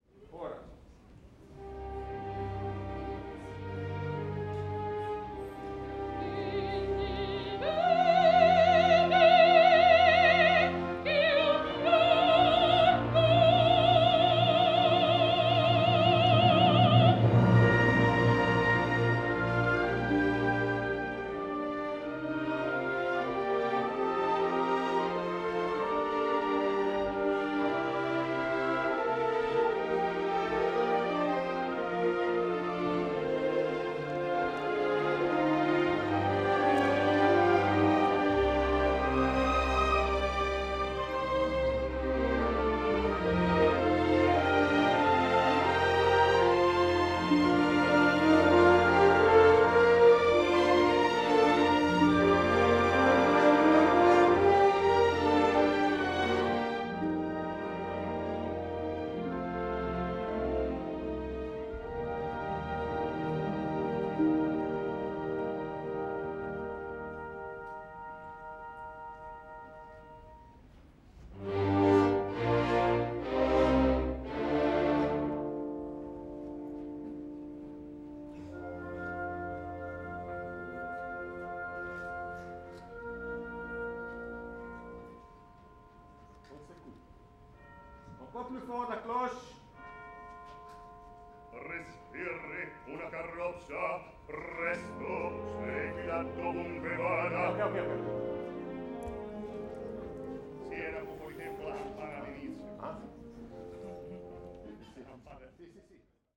répétition scène orchestre
Metz, Opéra Théâtre - KM184 ORTF / 4Minx